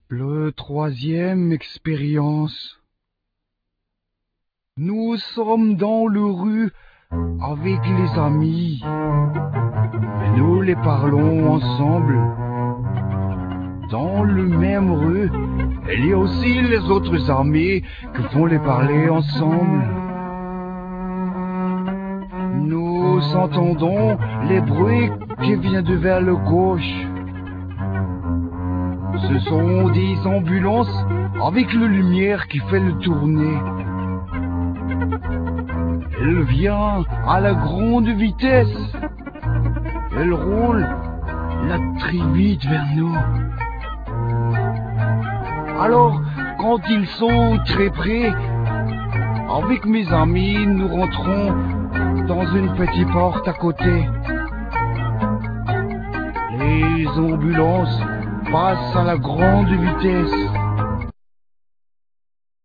Guitar
Drums,Percussions
Contrabass
Saxophones,Synthesizer
Vocal
Bass